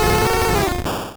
Cri de Goupix dans Pokémon Rouge et Bleu.